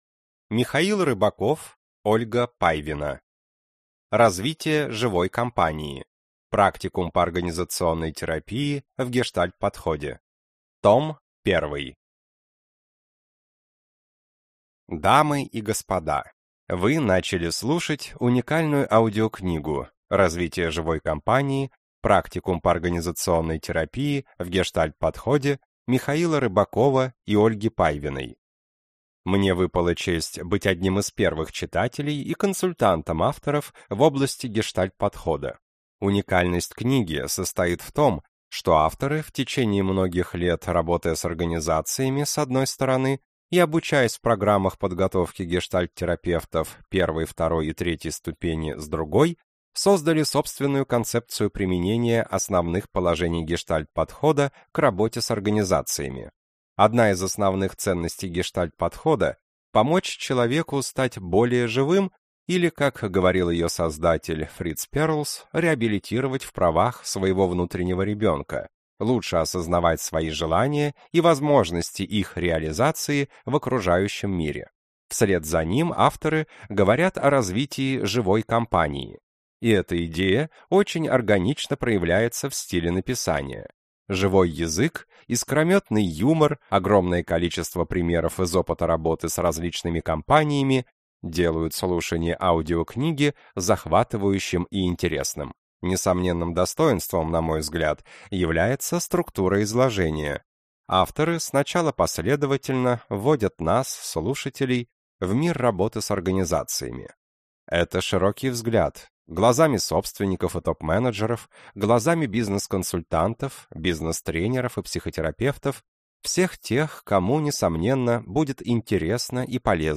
Аудиокнига Развитие живой компании. Практикум по организационной терапии в гештальт-подходе. Том 1 | Библиотека аудиокниг